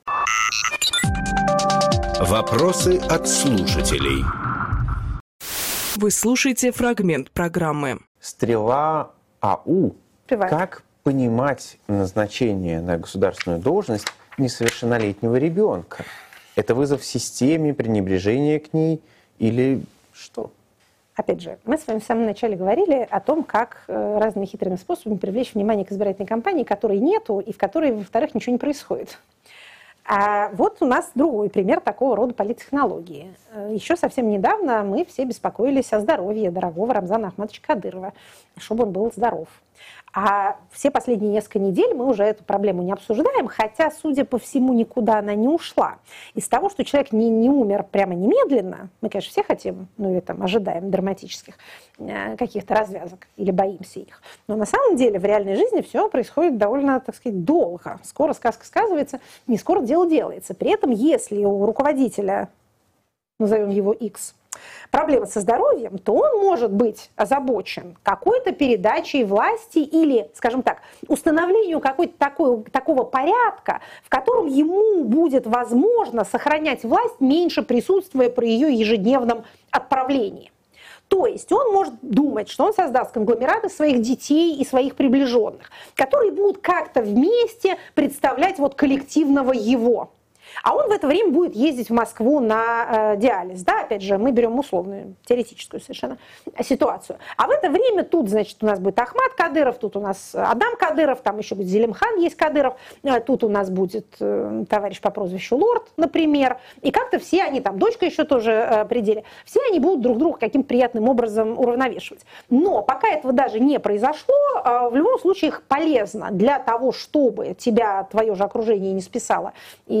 Екатерина Шульманполитолог
Фрагмент эфира от 7 ноября.